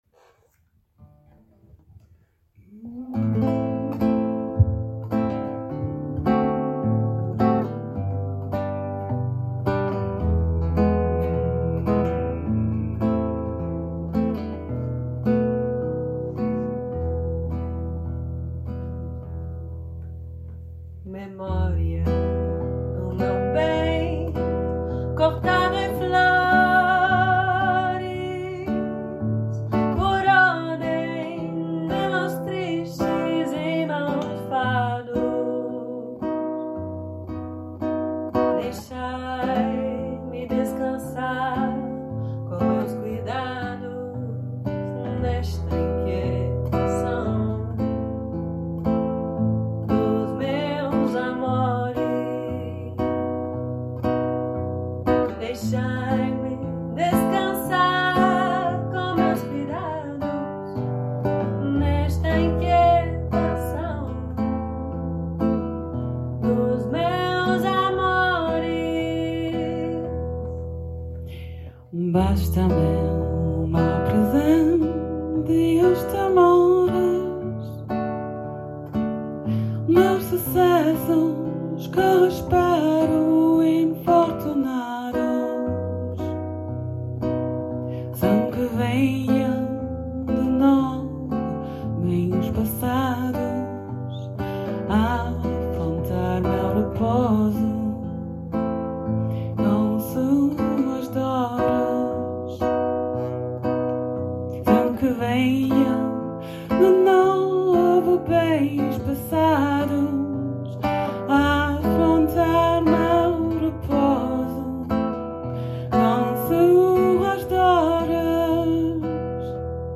Hier befindet sich eine Auswahl unseres Repertoires mit Sheets und einigen Audio-Aufnahmen der Stücke, die in den Proben gemacht wurden.